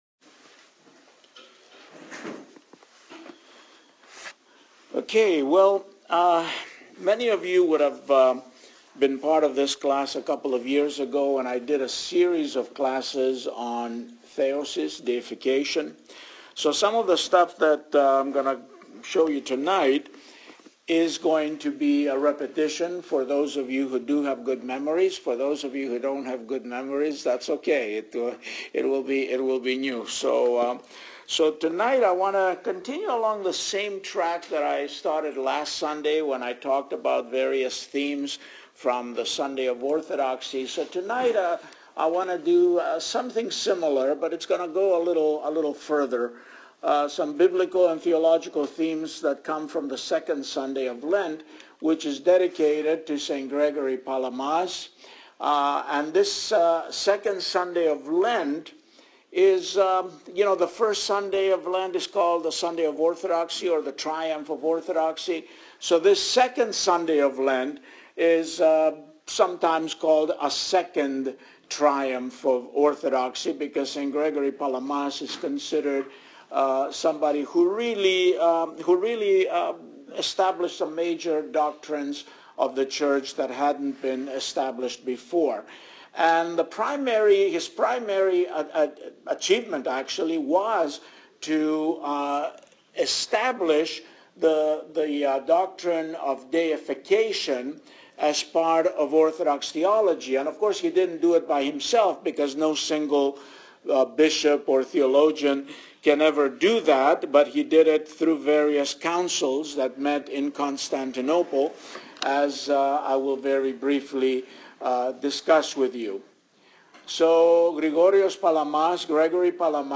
An audio file of the class is attached, together with a PDF version of the PowerPoint presentation.